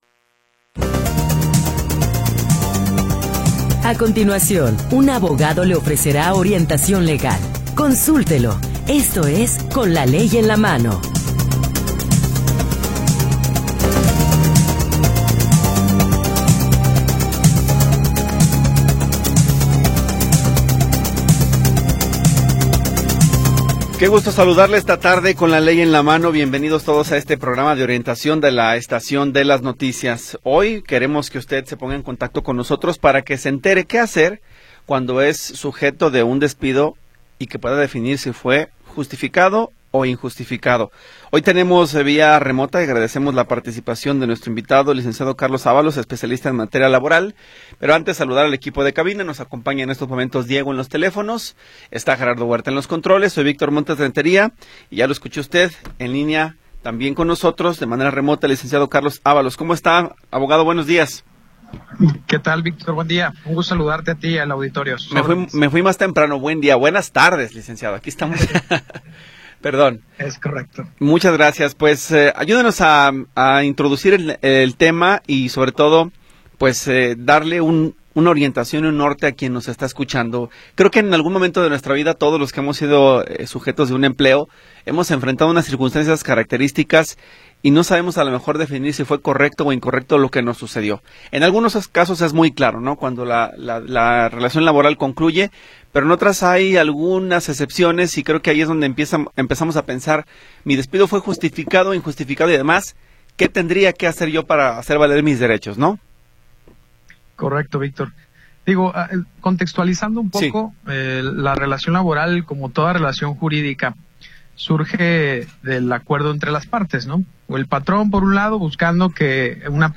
Programa transmitido el 19 de Agosto de 2025.